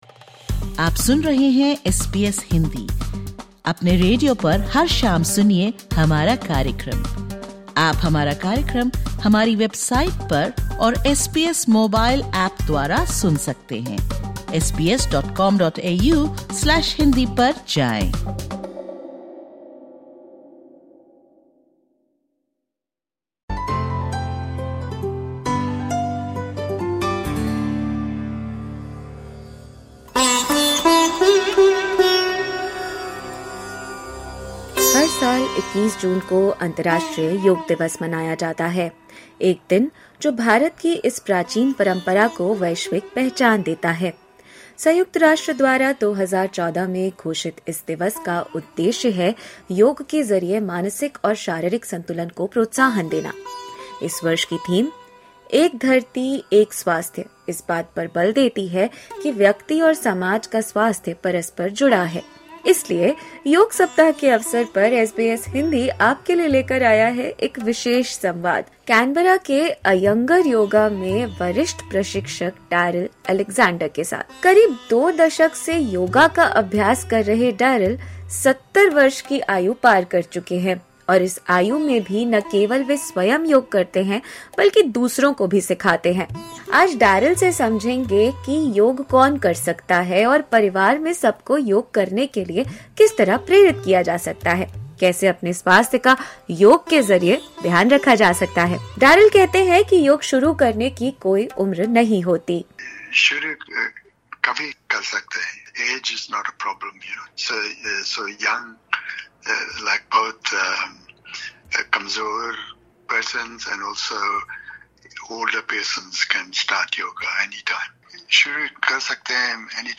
Disclaimer: The information given in this interview is of general nature.